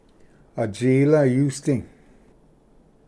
ᎠᏥᎳ ᏳᏍᏗ Pronunciation